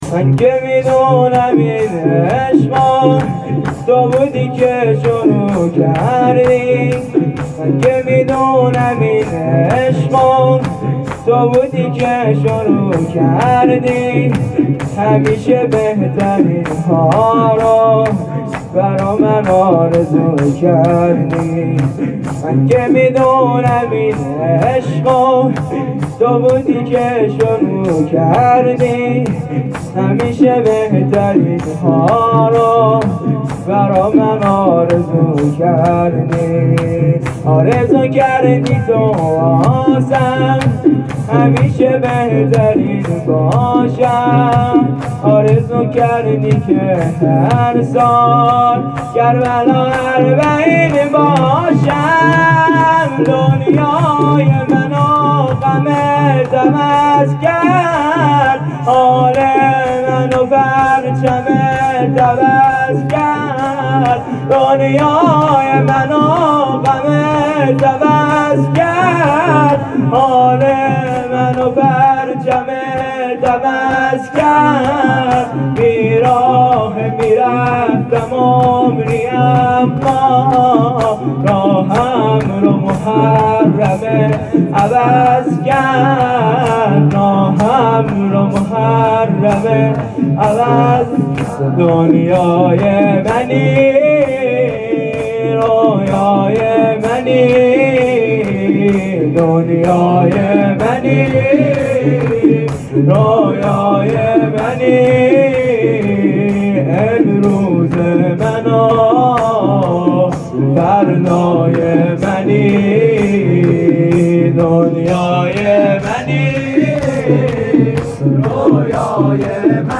اجرا شده در حسینیه ی زینبیون هیئت انصار الموعود فاطمیه سال 98